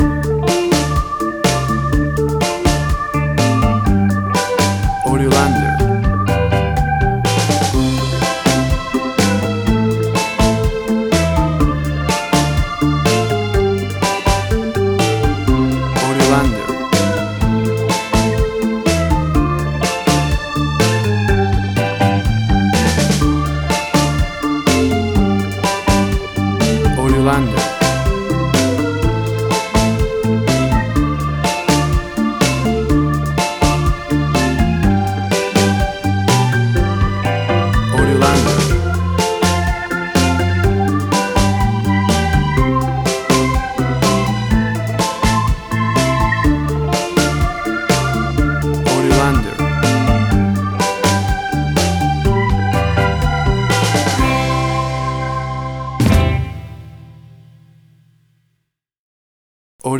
Tempo (BPM): 124